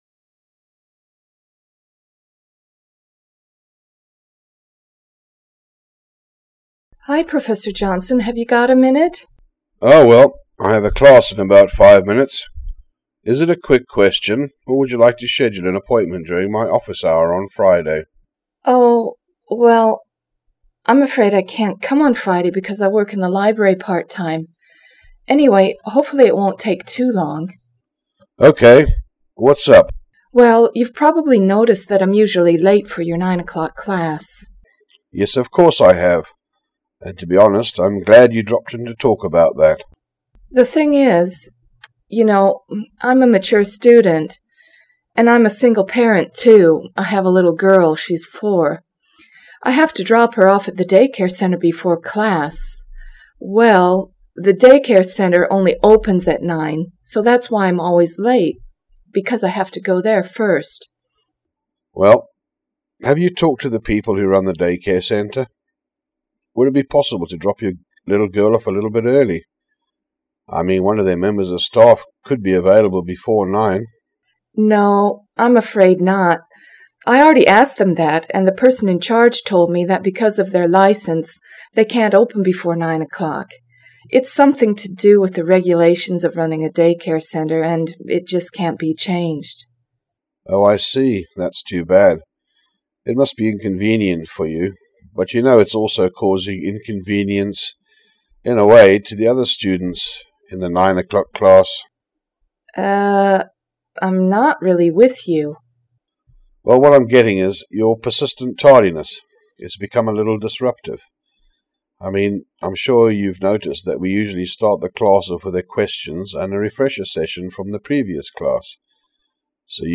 Listen to the following conversation between a student and a member of university staff.